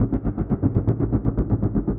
Index of /musicradar/rhythmic-inspiration-samples/120bpm